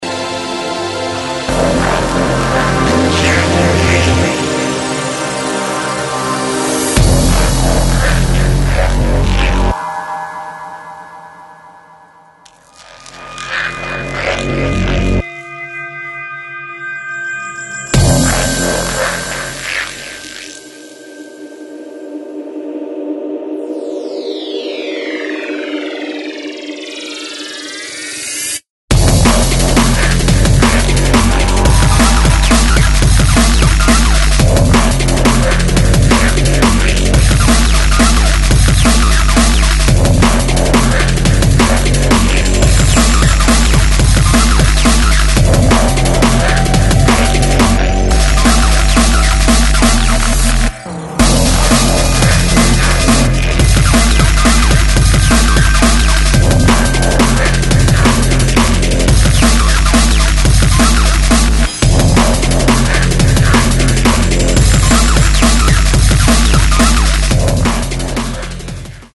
Styl: Drum'n'bass, Hardtek/Hardcore